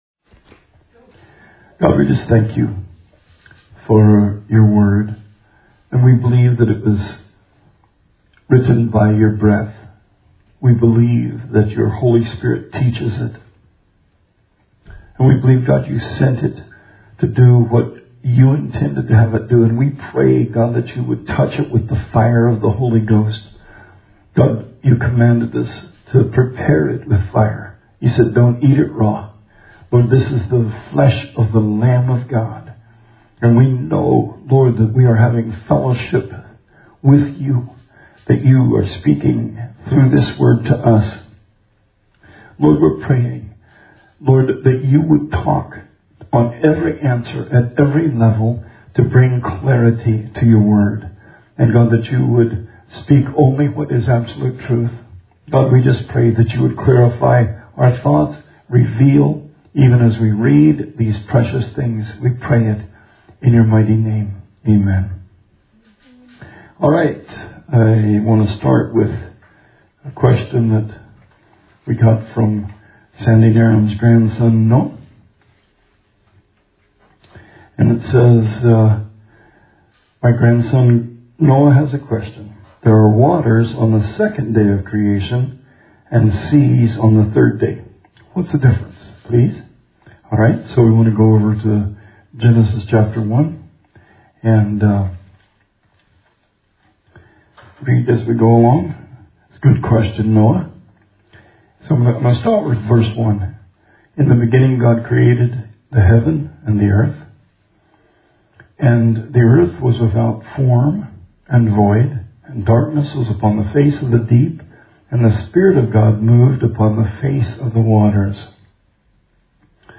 Bible Study 6/17/20